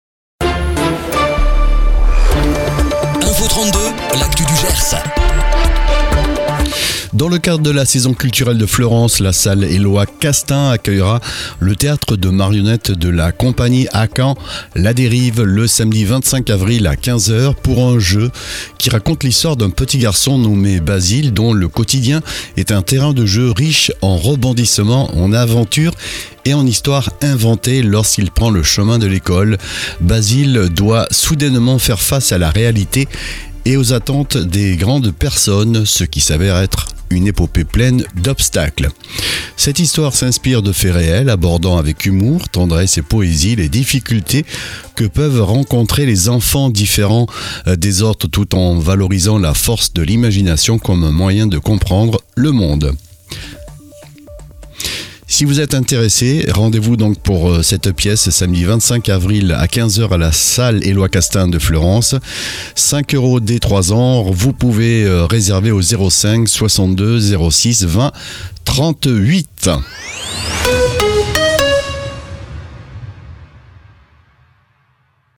Flash infos 27/03/2026